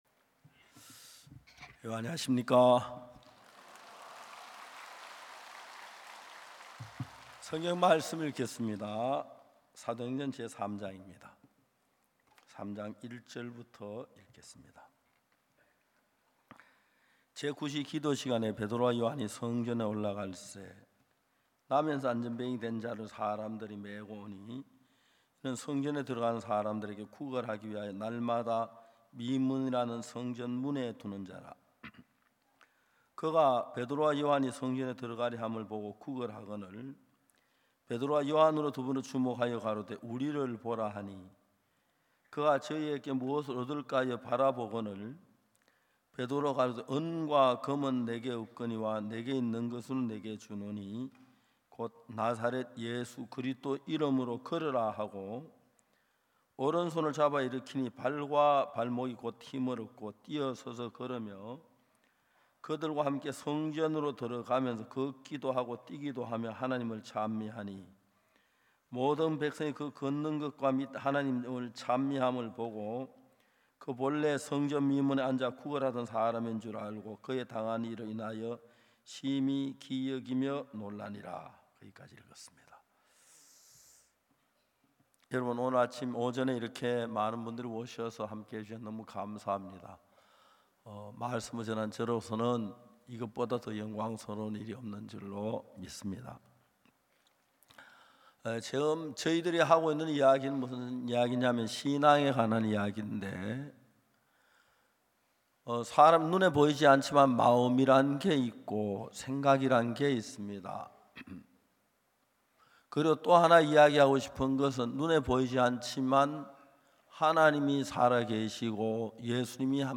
2024 후반기 부산 성경세미나